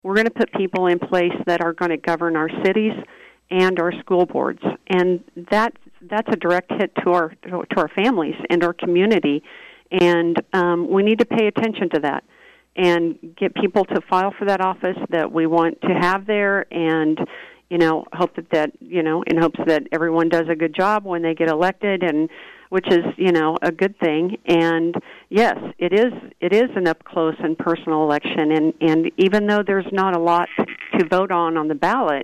Lyon County Clerk Tammy Vopat recently joined KVOE’s Morning Show to discuss the upcoming candidate filing and withdrawal deadline.